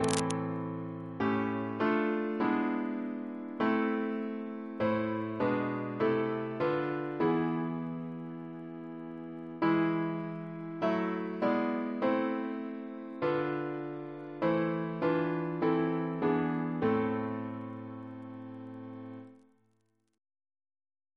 Double chant in A minor Composer: Arthur H. Mann (1849-1929) Reference psalters: ACB: 100; H1982: S435; RSCM: 93